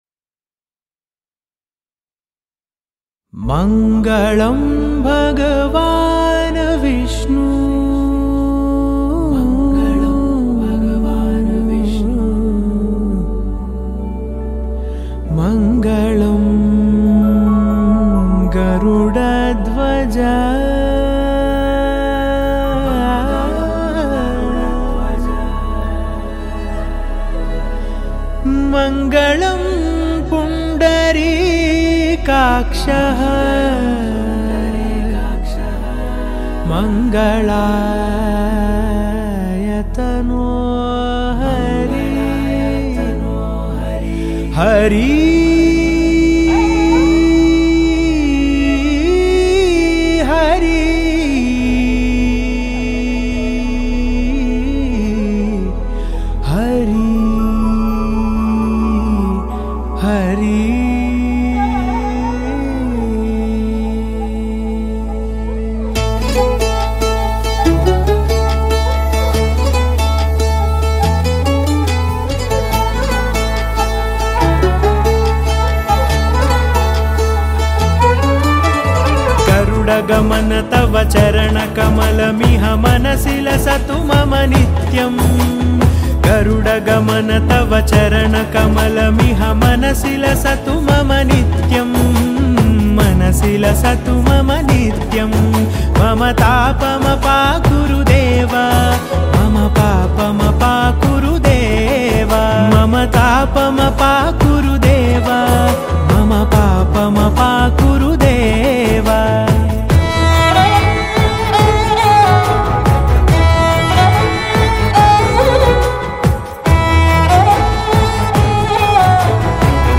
devotional song
The mood feels calming, spiritual and uplifting.